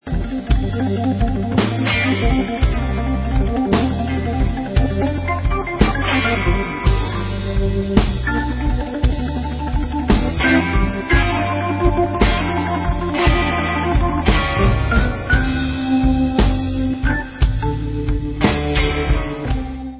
New studio album